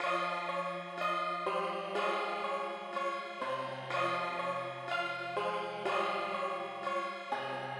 描述：l 所用的序列是Omnisphere，调是F，音阶是和声小调。
Tag: 123 bpm Ambient Loops Bells Loops 1.31 MB wav Key : F